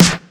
Snares
Shl_Snr.wav